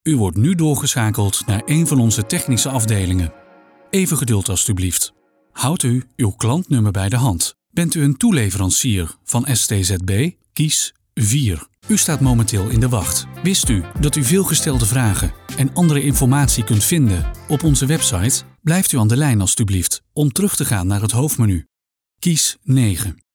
Zuverlässig, Freundlich, Corporate
Erklärvideo